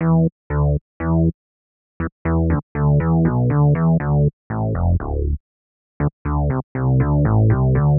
29 Bass PT1.wav